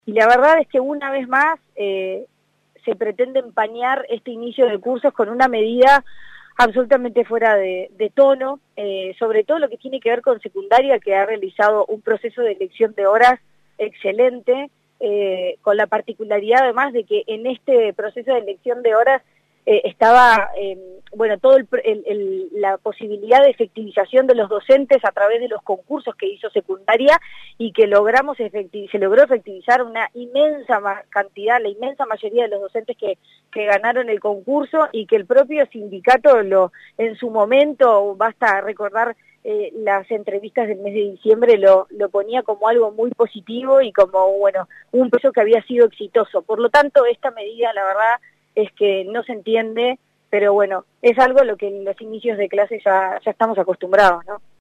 La expresidenta de Anep y candidata a la Intendencia de Montevideo por el Partido Colorado, dentro de la Coalición Republicana, Virginia Cáceres, en diálogo con 970 Noticias, calificó la medida tomada por profesores del liceo de Las Piedras quienes realizarán un paro este miércoles, fecha marcada para el inicio de clases, como “fuera de tono”.